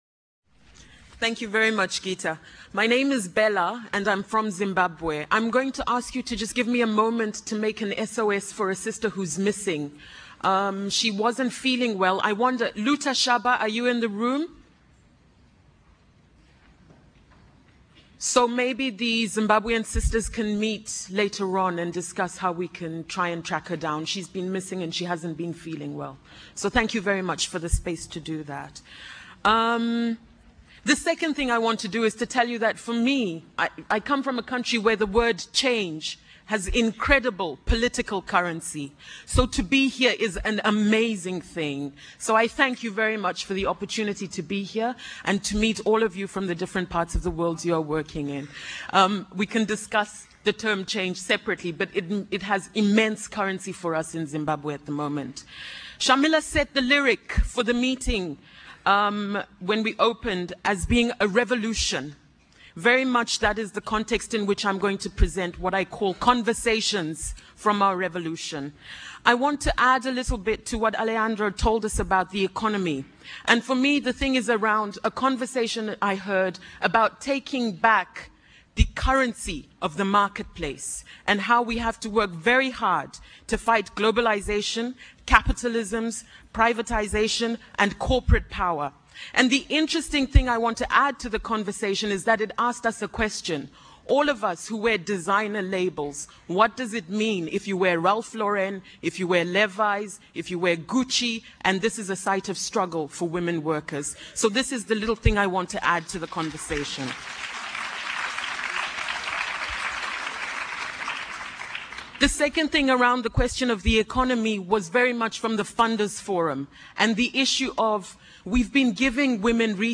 Be part of this participatory closing plenary and hear from these wise women of all ages who will creatively show us all just how change happens.